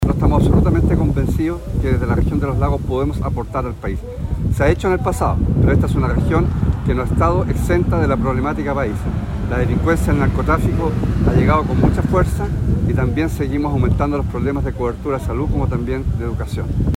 Por su parte, Alejandro Santana debió desplazarse a la comuna de Chonchi, para votar en el local del sector rural de Púlpito, desde donde destacó el aporte que puede hacer el gobierno regional, recuperando vitalidad económica.